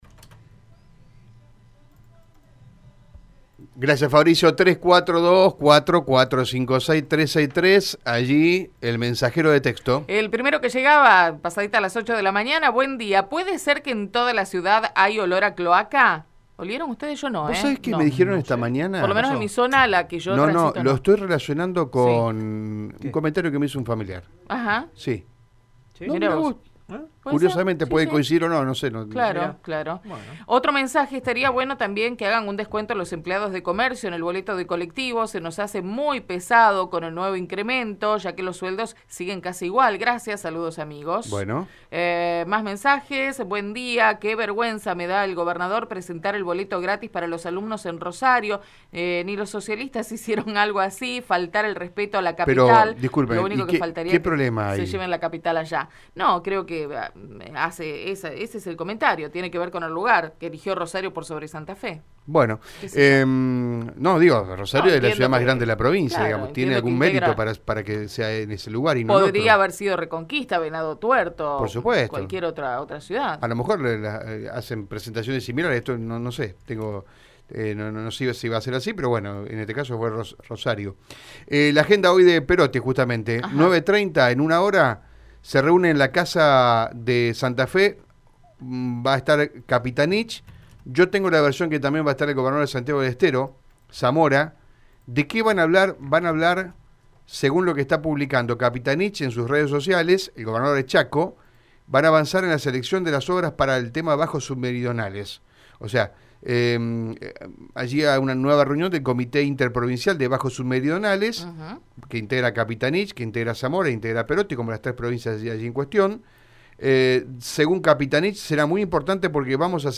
En conferencia de prensa, la ministra Sonia Martorano anticipó que en la próximas horas llegarían las dosis de vacunas contra el Covid-19 a la provincia de Santa Fe.
INFO-RAFAELA-Rafaela-Sonia-Martorano-Ministra-de-Salud.mp3